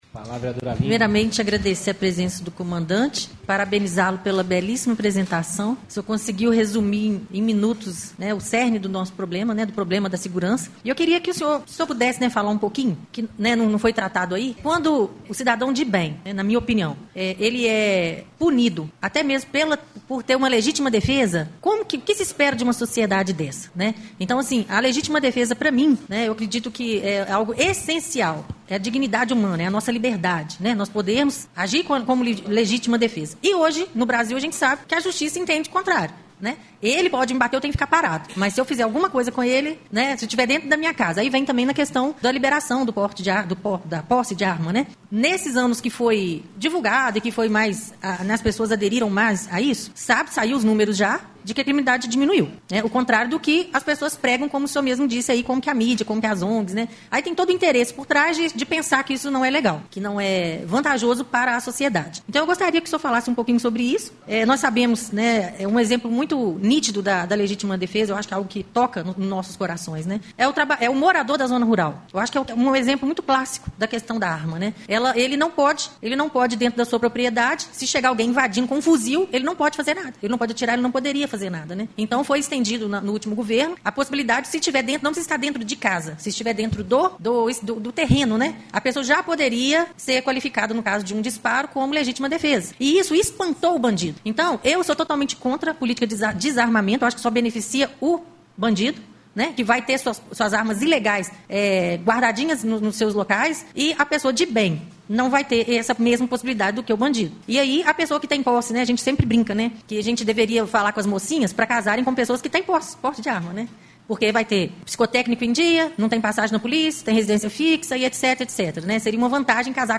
Vereadora Aline Moreira Melo em sessão da Câmara Municipal de Ubá(9/10/23)